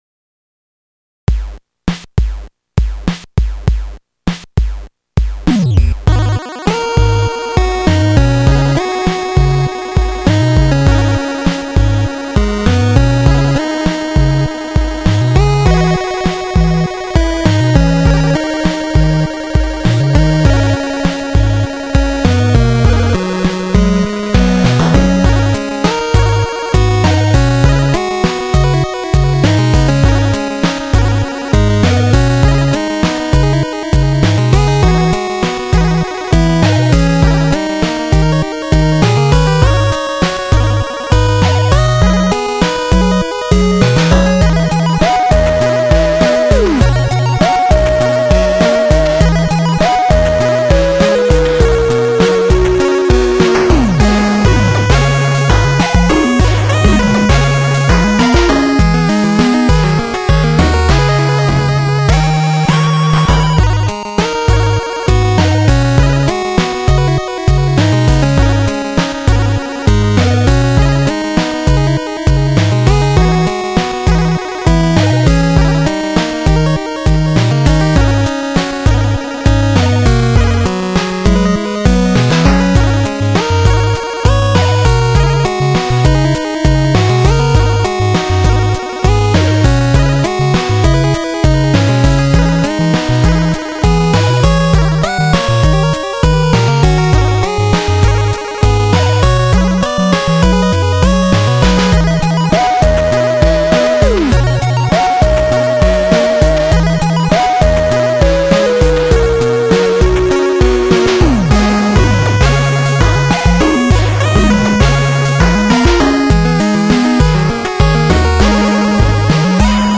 ※ (　　)内は、使用音源チップです。
(2A03)